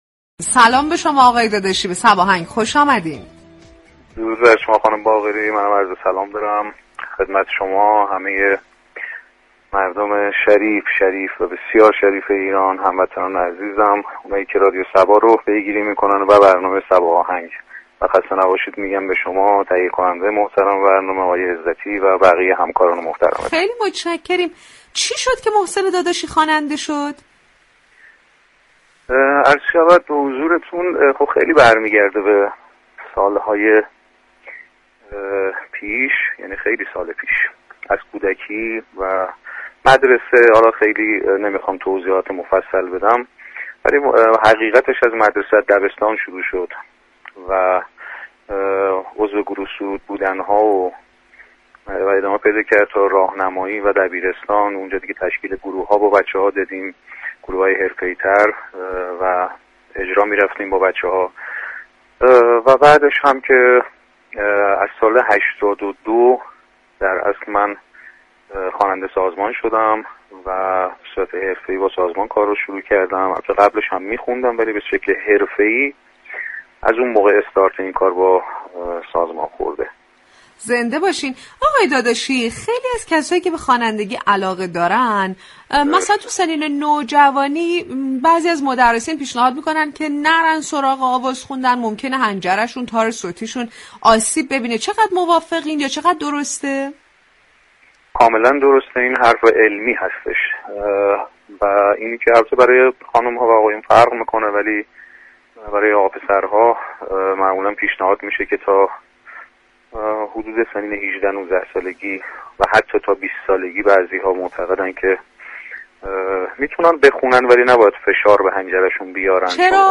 گفتگوی ویژه